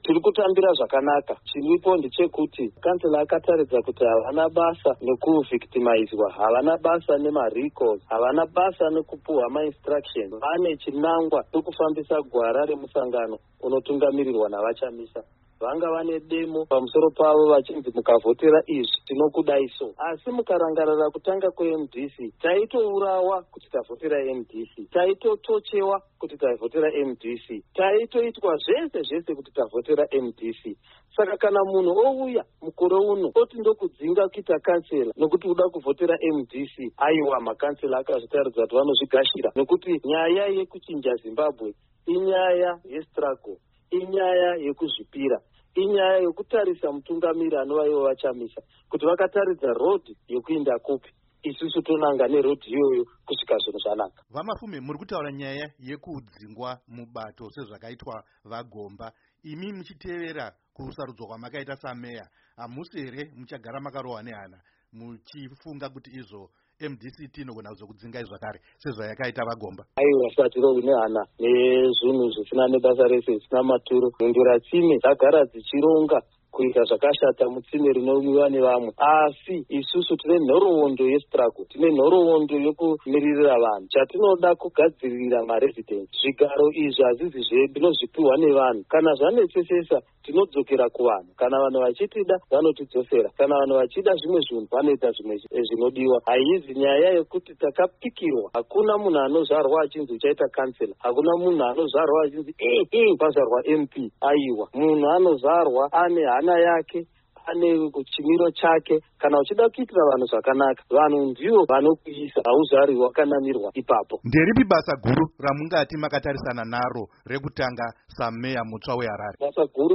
Hurukuro naVaJacob Mafume